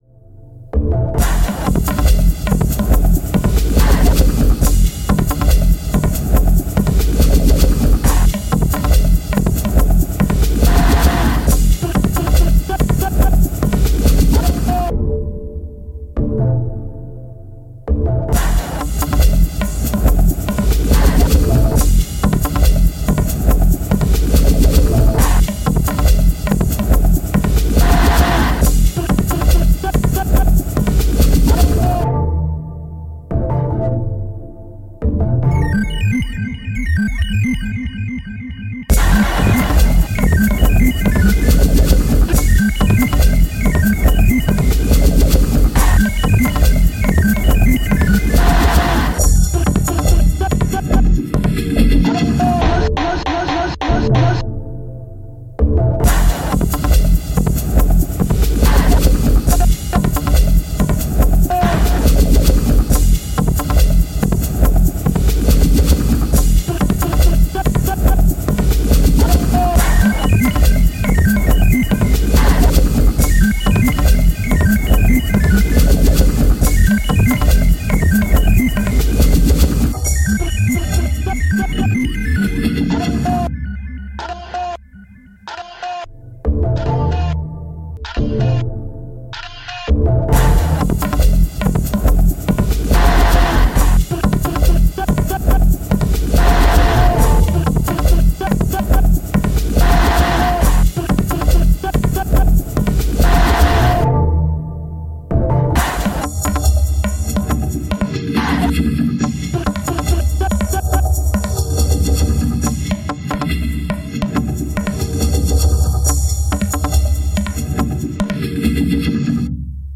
INTERVISTA COMPILATION "MUSIC FOR PALESTINE" A PUZZLE 6-5-2024